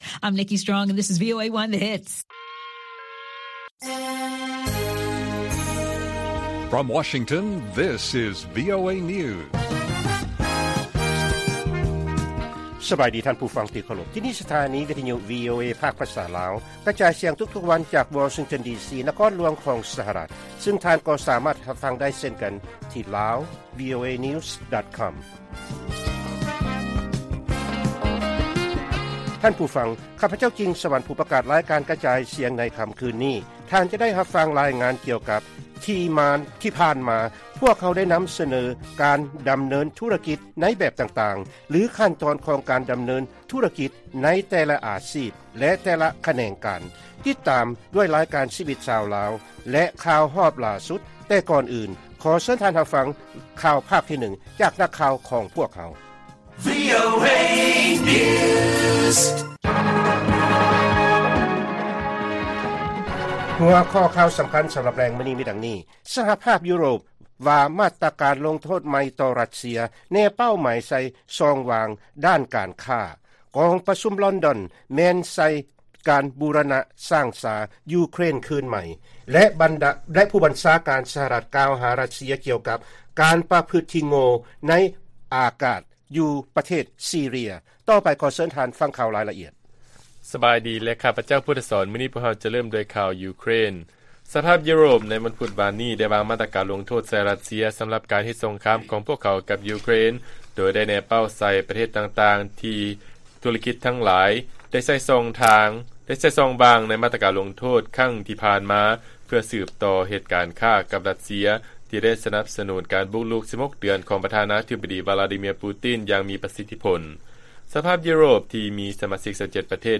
ລາຍການກະຈາຍສຽງຂອງວີໂອເອ ລາວ: ສະຫະພາບຢູໂຣບ ວາງມາດຕະການລົງໂທດໃໝ່ ຕໍ່ ຣັດເຊຍ ໂດຍແນເປົ້າໃສ່ຊ່ອງຫວ່າງດ້ານການຄ້າ